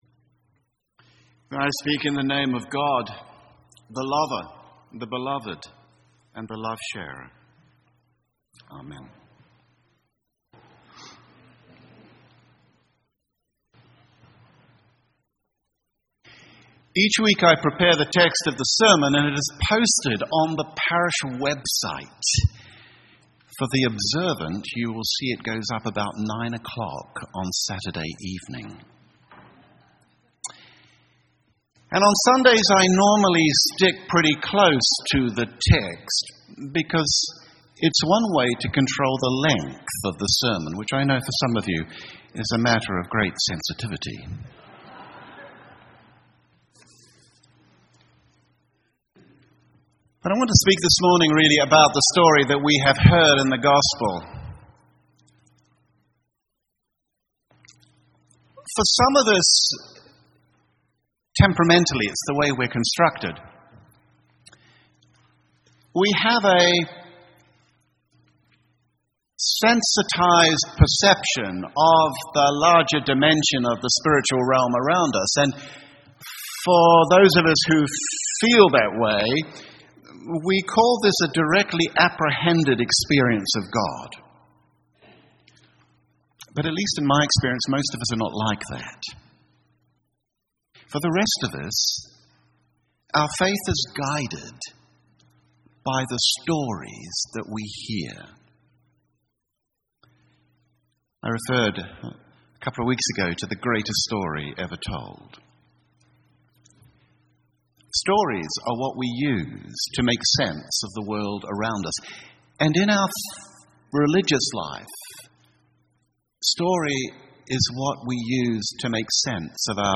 A sermon for Easter 3